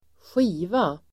Uttal: [²sj'i:va]